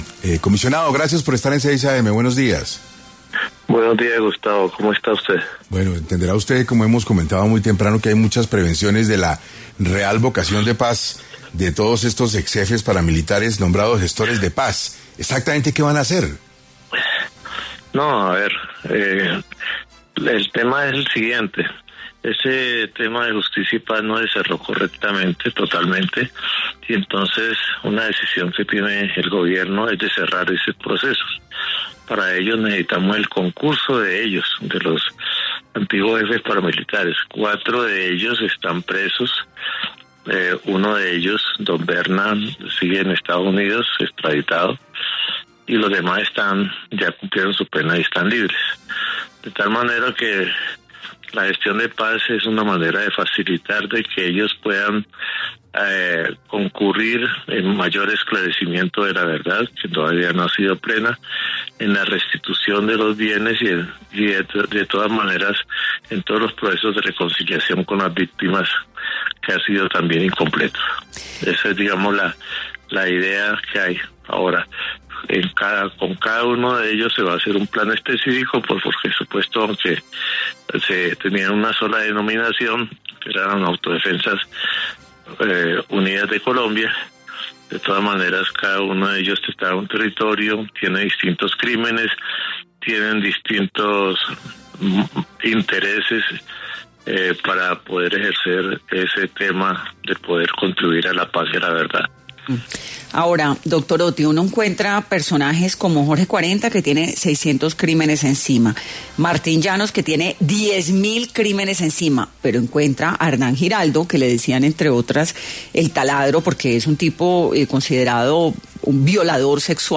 En diálogo con 6AM de Caracol Radio, Otty Patiño, Consejero Comisionado para la Paz, reiteró que esta nueva mesa de diálogo, que sería la primera con un grupo armado ya inexistente, tiene como fin terminar” la desmovilización de las AUC que ocurrió hace ya dos décadas bajo el mandato de Álvaro Uribe Vélez.